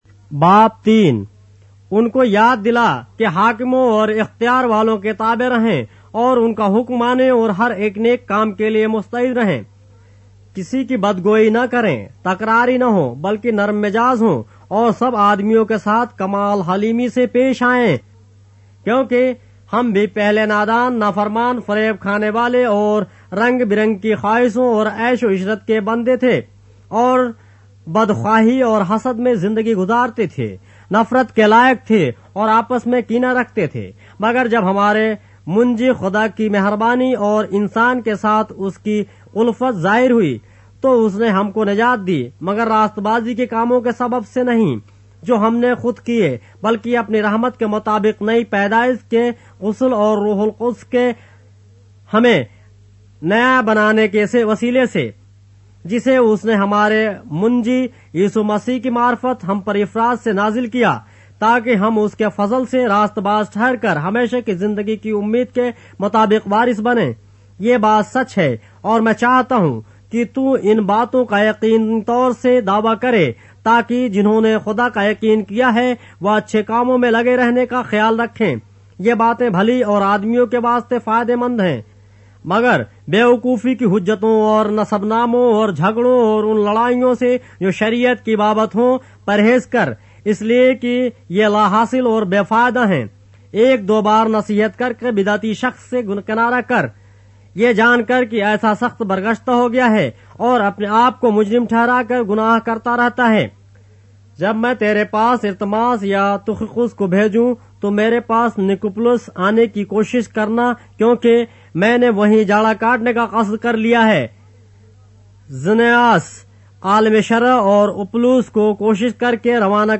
اردو بائبل کے باب - آڈیو روایت کے ساتھ - Titus, chapter 3 of the Holy Bible in Urdu